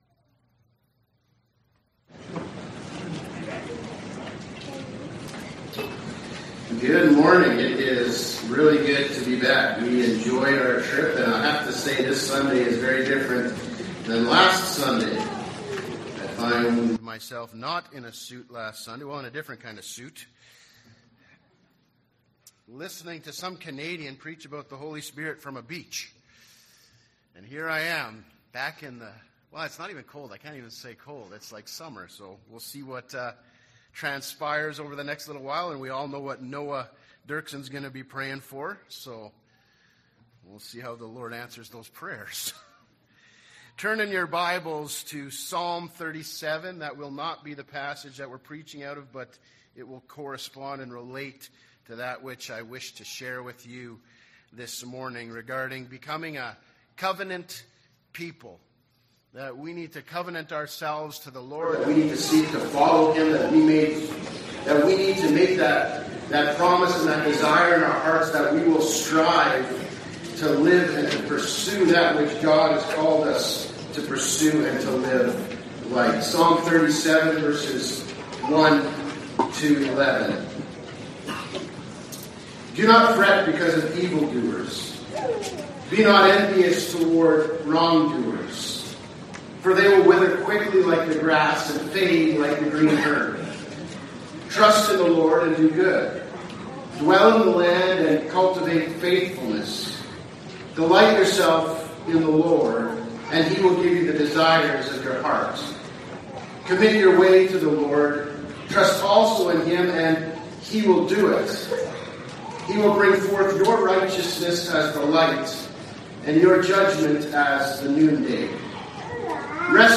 Sermon Becoming a Covenant People